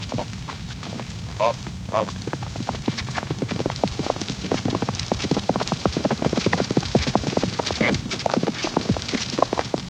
Tires crunching through light snow and soil, growing louder as the wolves run.” 0:10
padded-fast-footsteps-of--6xqmn6pl.wav